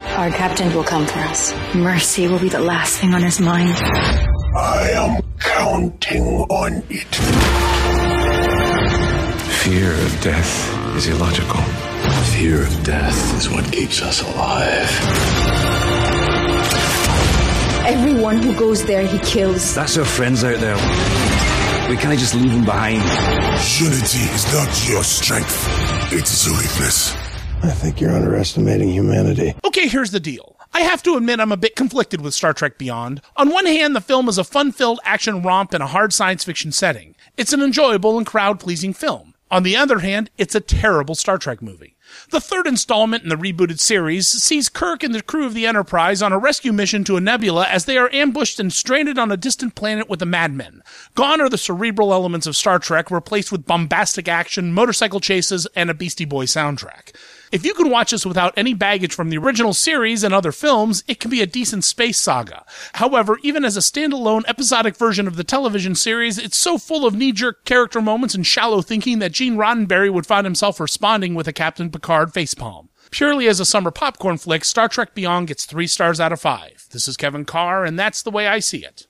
‘Star Trek Beyond’ Radio Review